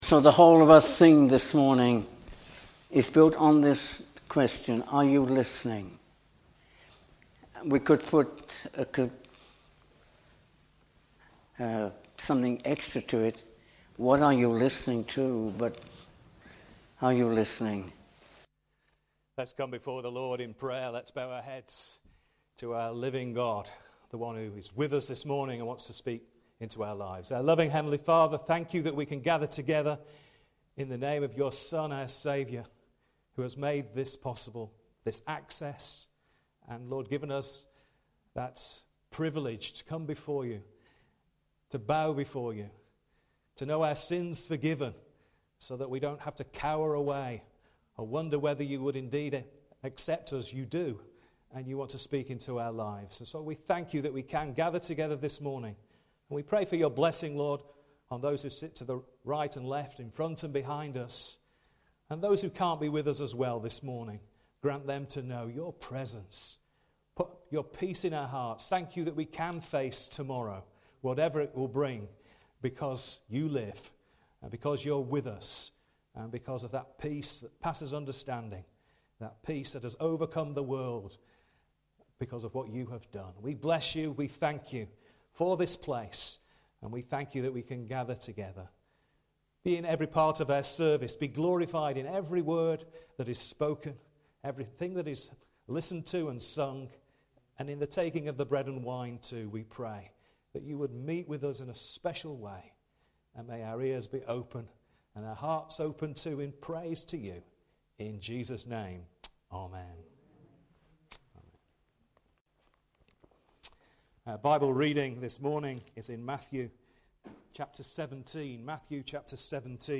Sermon – 18th April 2021